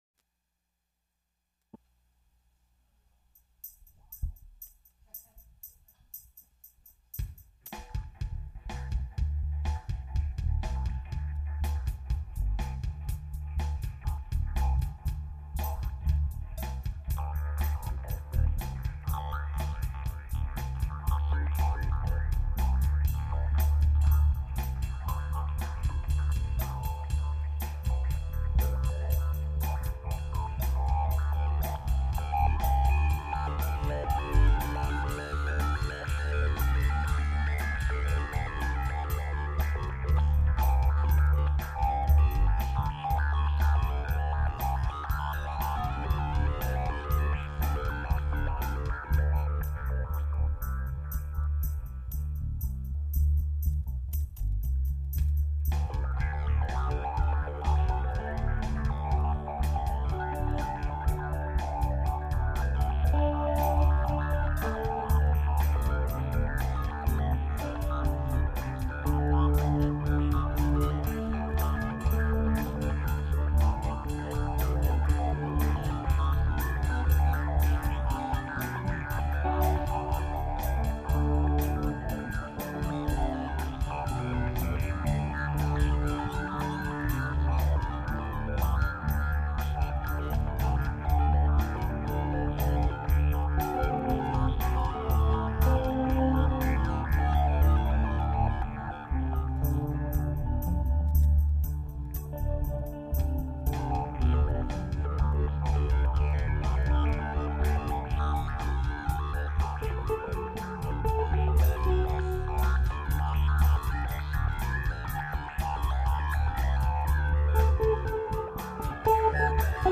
Kontrol, is their midwest version of techno.
processed mouth harp
Guitar, bass, and drum machine programming